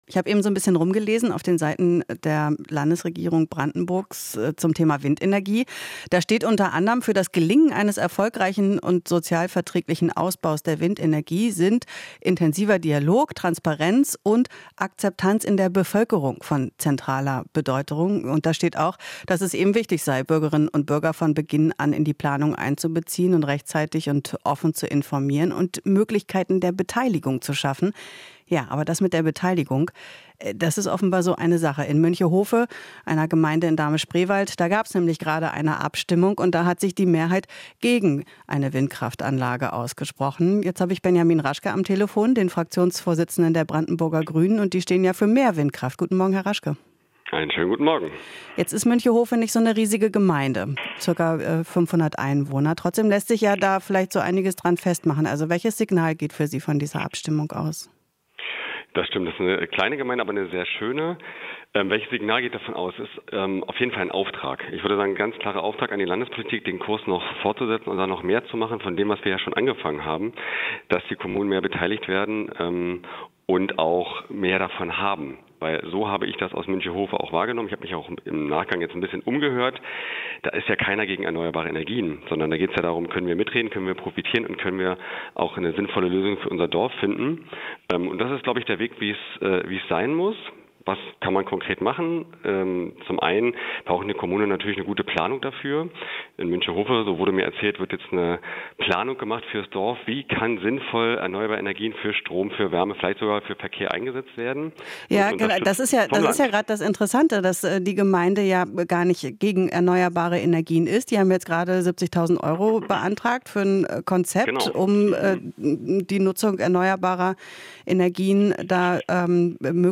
Interview - Münchehofe: Erneuerbare ja - Windkraft nein